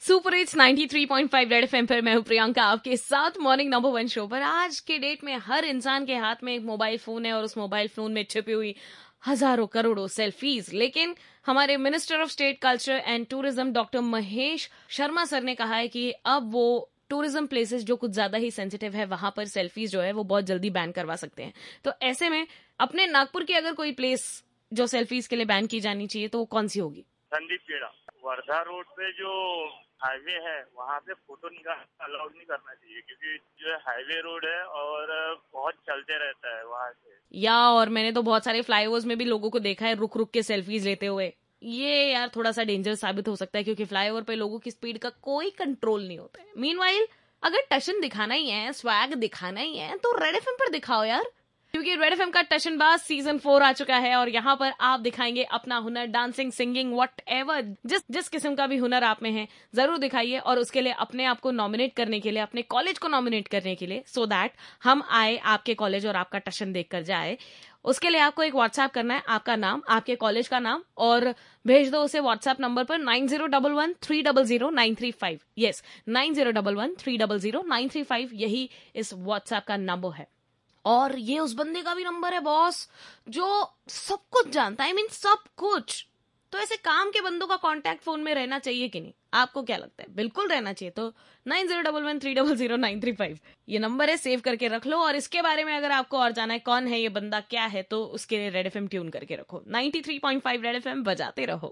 interacting callers